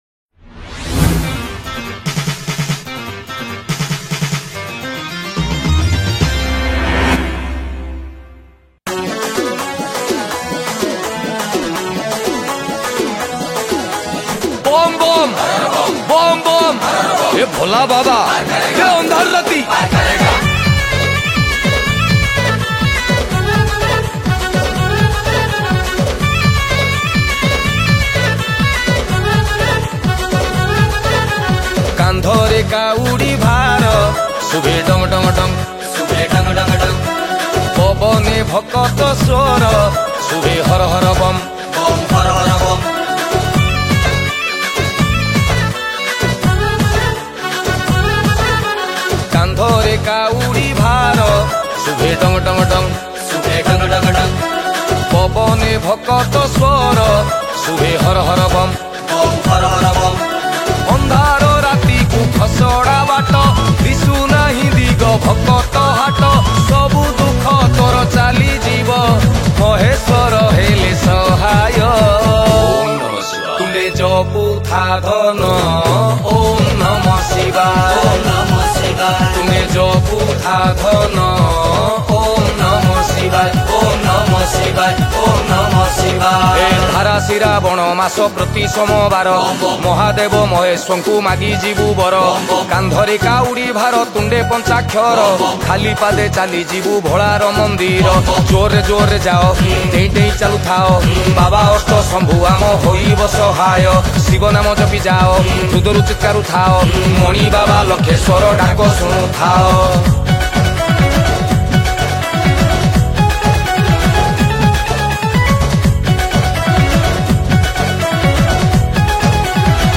Bolbum Special Song Songs Download